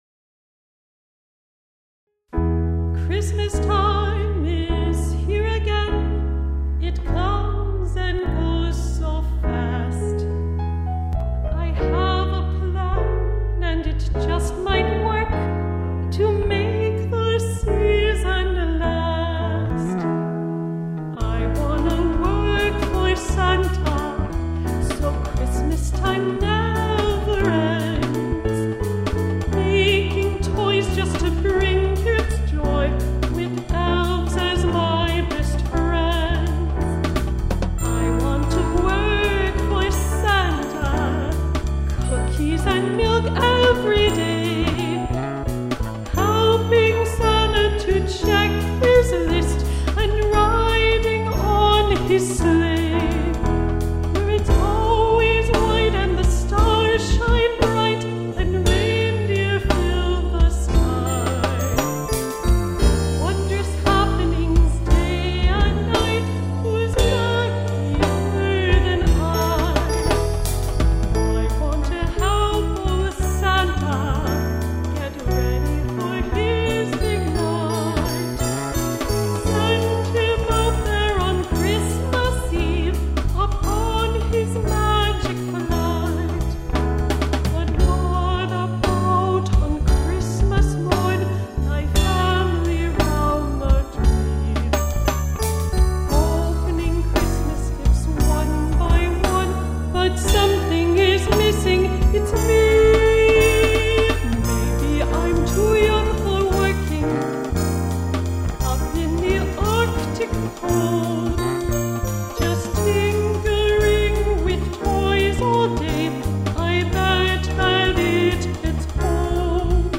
Play Song unknown vocalist
bass